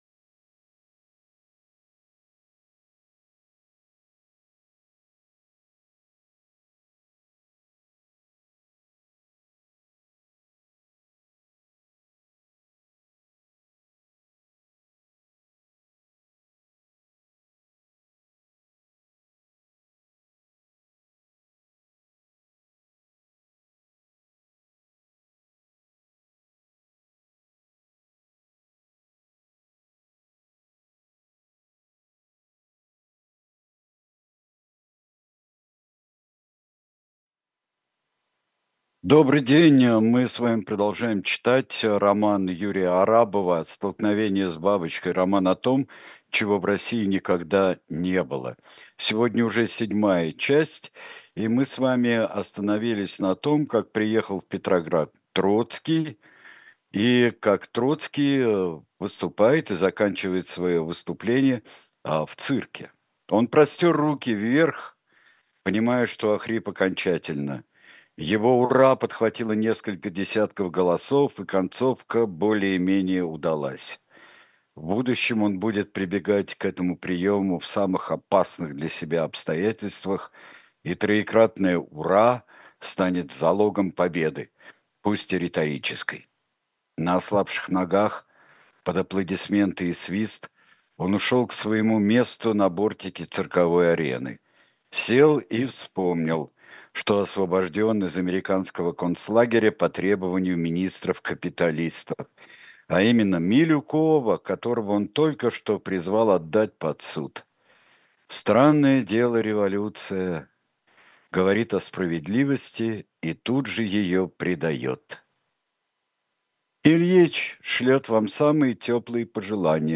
Сергей Бунтман читает роман Юрия Арабова.